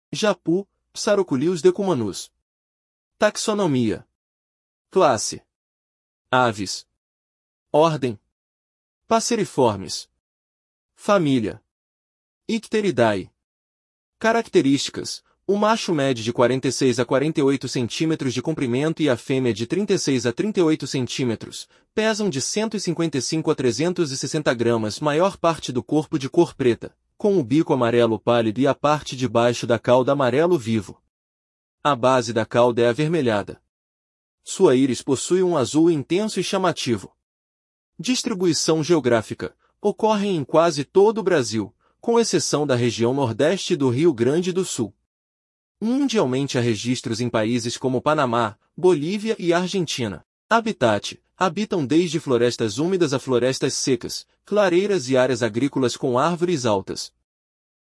Japu (Psarocolius decumanus)
Família Icteridae